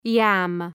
Προφορά
{jæm}